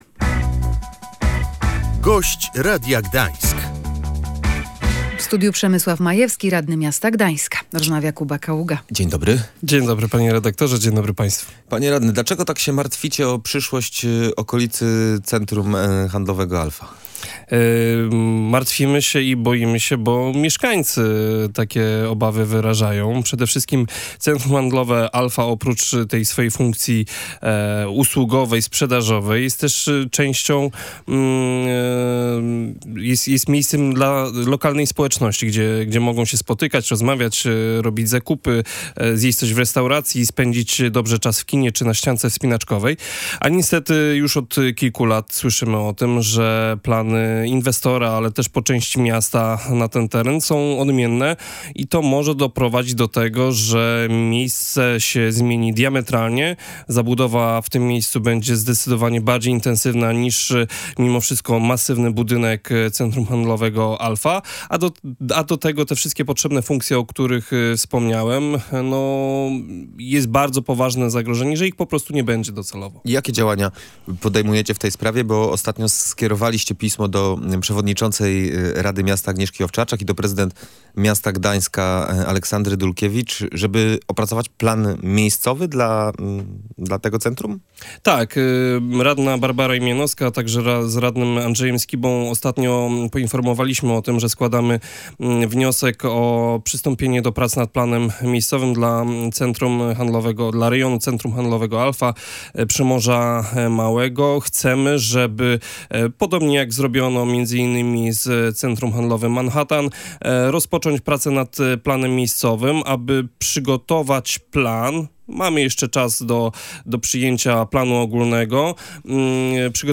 Samorządy wspólnie powinny od nowa opracować zasady jego działania – mówił na naszej antenie gdański radny Prawa i Sprawiedliwości Przemysław Majewski, który był „Gościem Radia Gdańsk”.